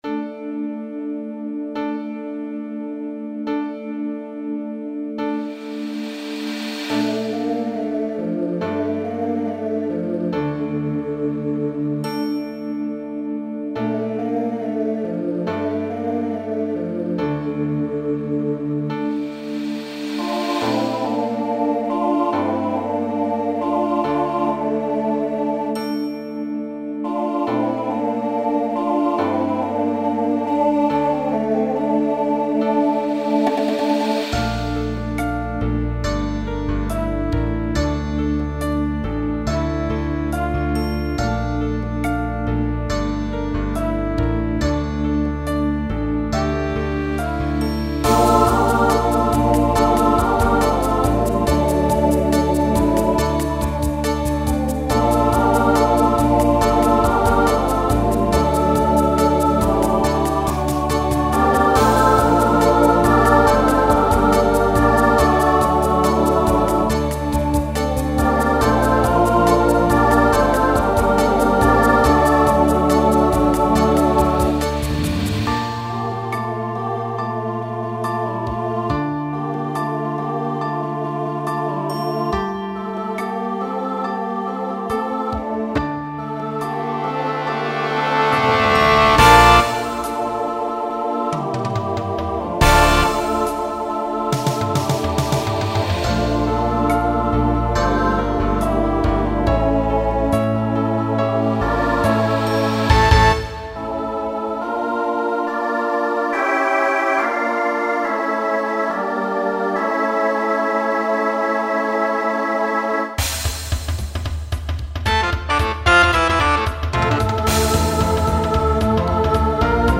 Genre Rock , Swing/Jazz
Voicing SATB